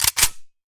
select-smg-2.ogg